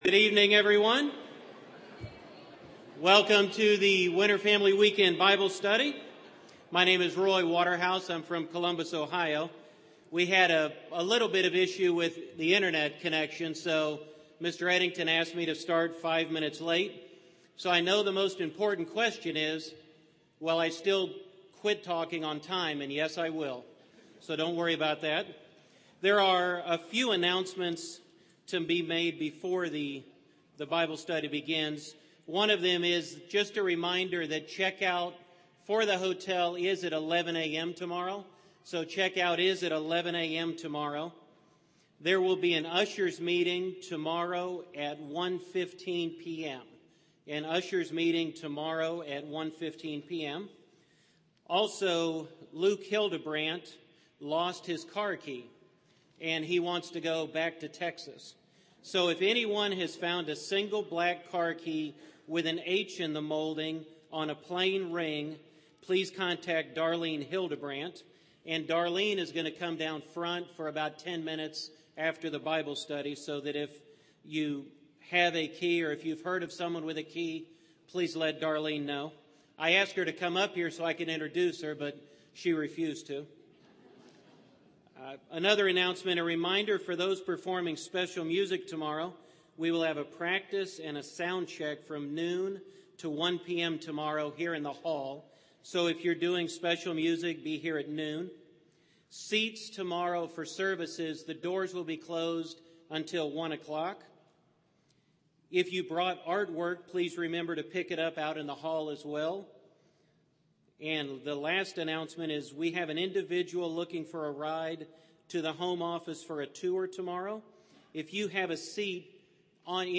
This Bible Study was given during the 2014 Winter Family Weekend.